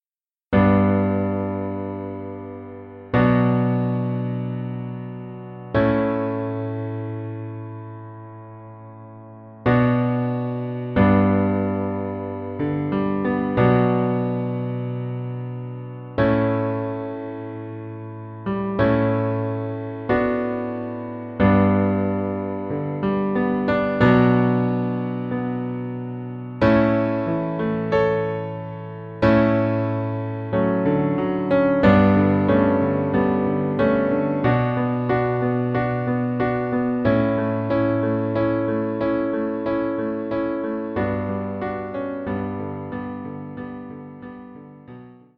version accoustique piano